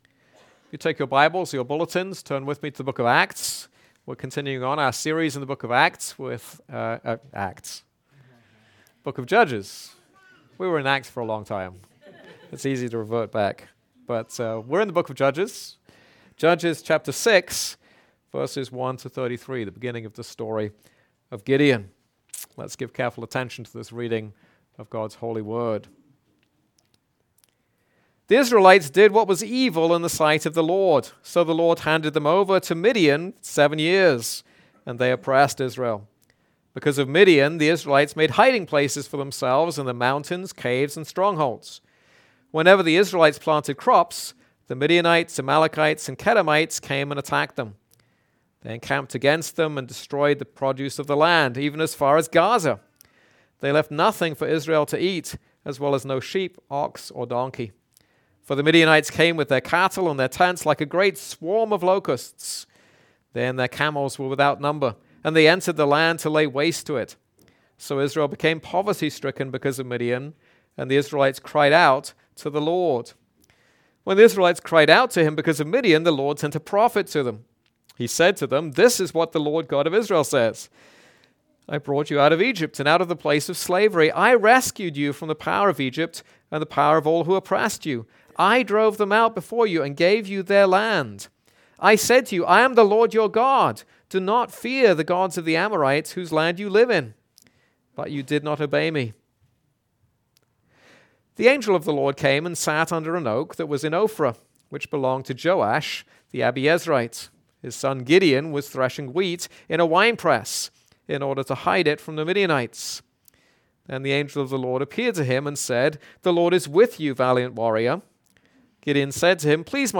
This is a sermon on Judges 6:1-33.